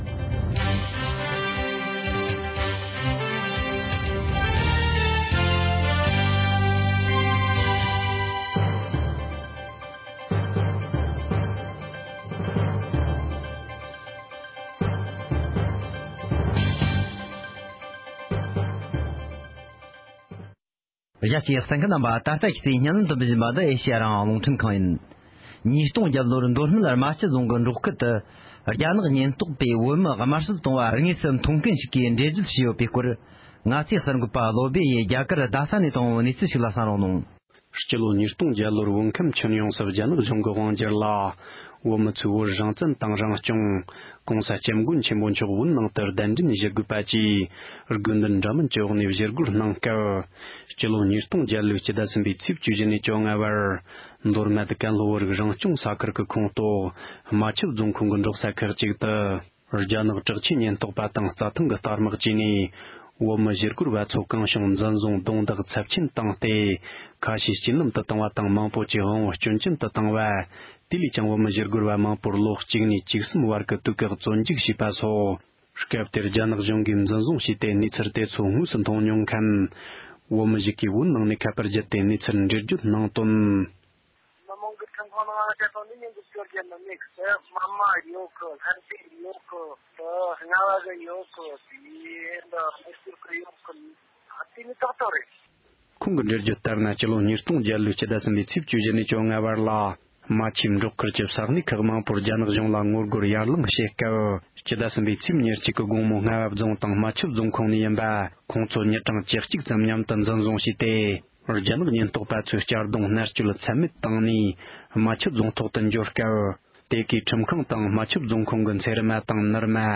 ༄༅༎ཕྱི་ལོ་ཉིས་སྟོང་བརྒྱད་ལོར་བོད་ནང་གི་བོད་མི་ཚོས་རྒྱ་ནག་གིས་བོད་མིའི་ཐོག་འཛིན་པའི་སྲིད་ཇུས་ལ་གཞི་རྒྱ་ཆེ་བའི་ངོ་རྒོལ་གྱི་ལས་འགུལ་སྤེལ་སྐབས།